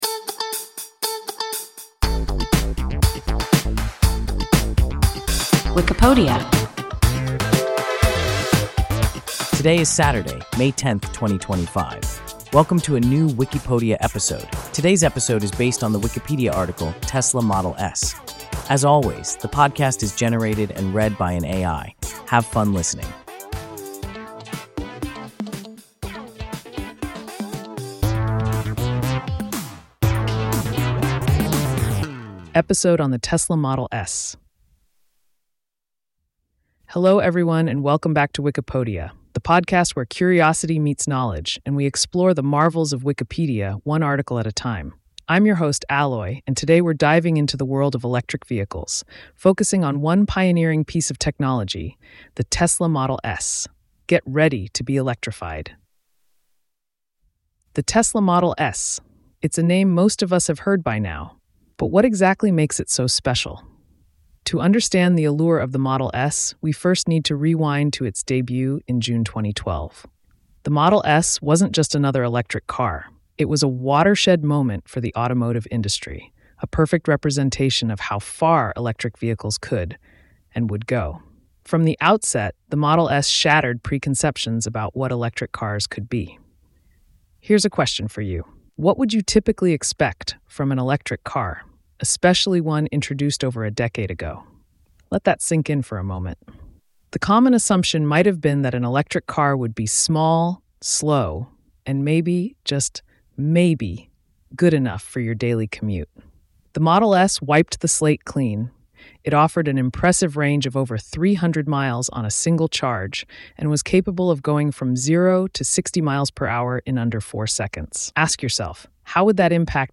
Tesla Model S – WIKIPODIA – ein KI Podcast